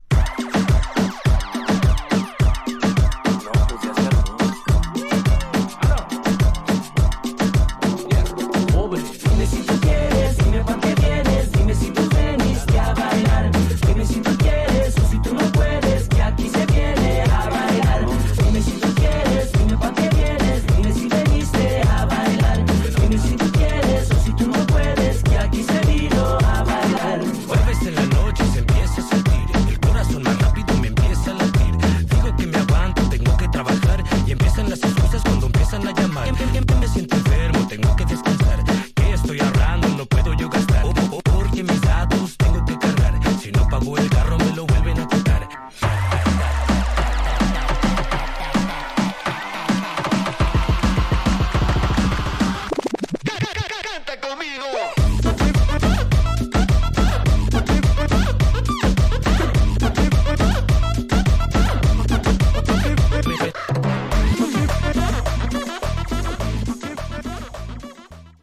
Tags: Cumbia
Super bailables: cumbia con raps, rock, housito.